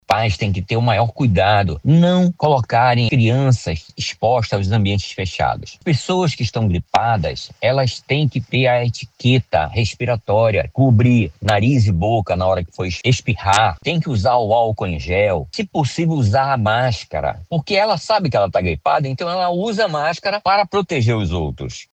SONORA02_MEDICO.mp3